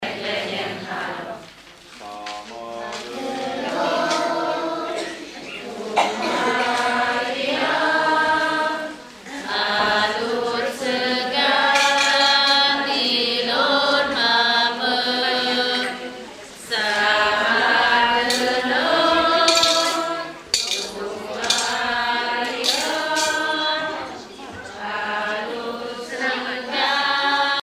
Vallasos ének
Pièce musicale éditée